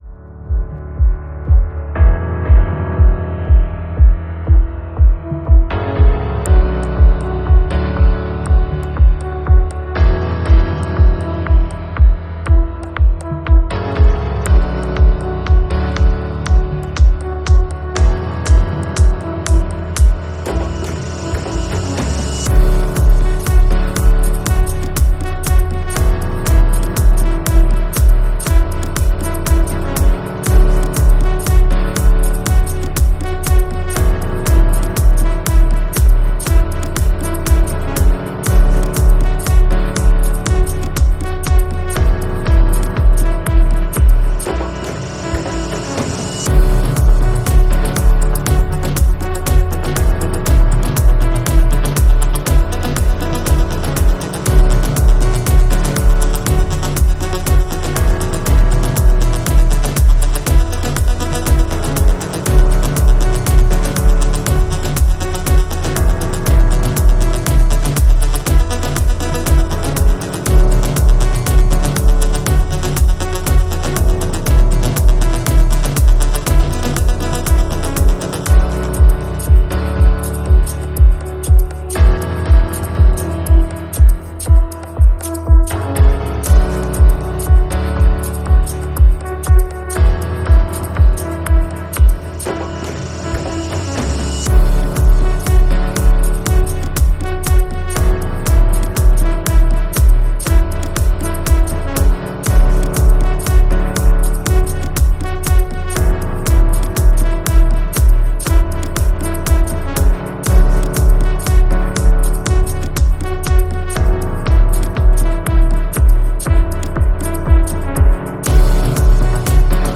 Musique dynamique libre de droit pour vos projets.